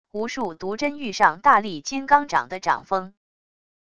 无数毒针遇上大力金刚掌的掌风wav音频